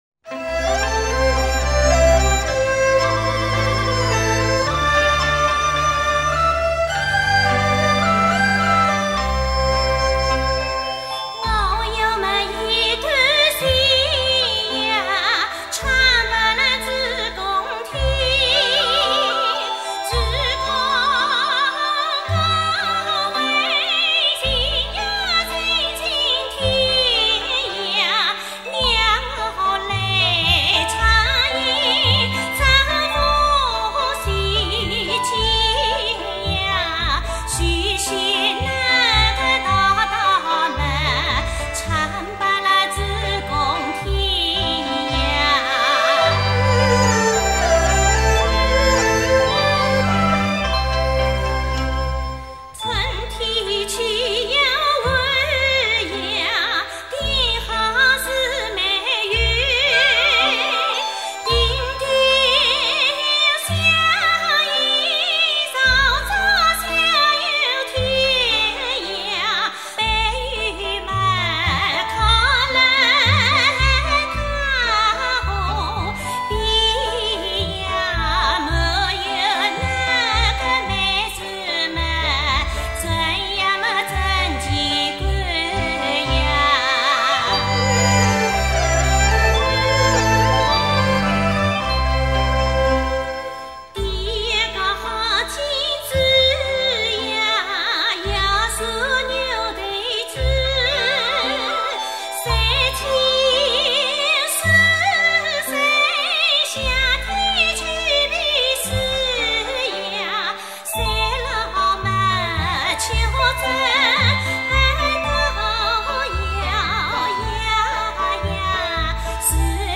[15/9/2009]原生态系列 江苏原生态民歌 比较地道！
无锡景 无锡县民歌 我有末一段情呀，唱拨拉诸公听。